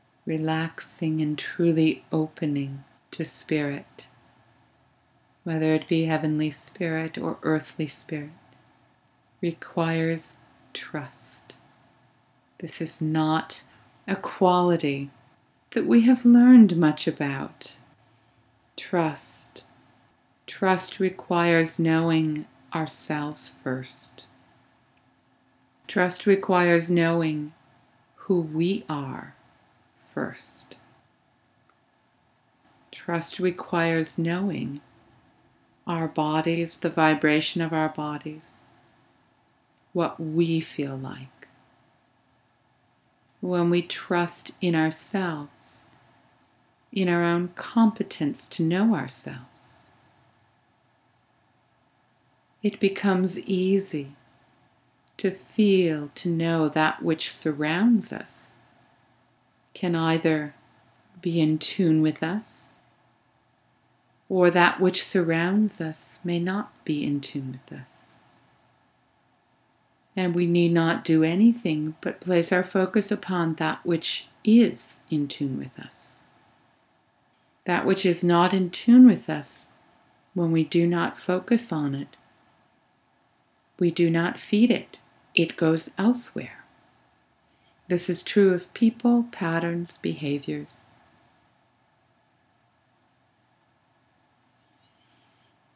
I included the short toning passage that occurred spontaneously during the meditation